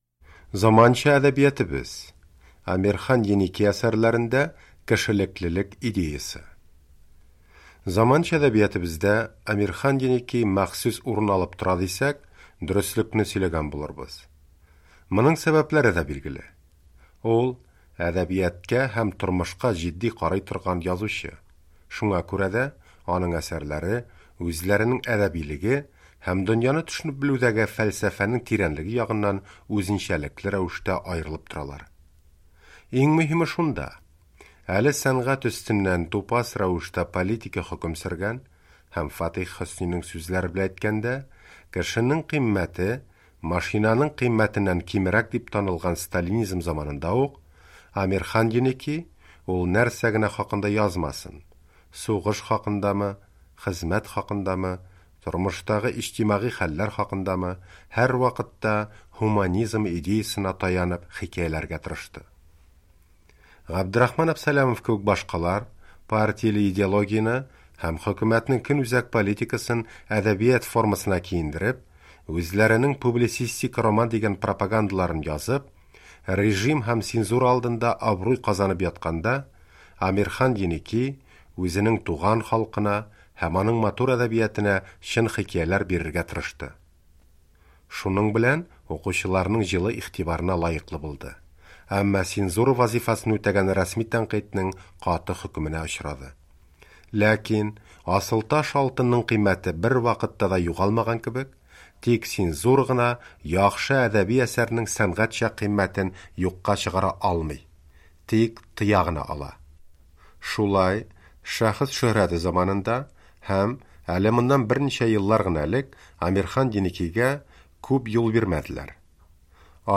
Радиобыз архивыннан "Заманча әдәбиятыбыз" тапшыруын тәкъдим итәбез.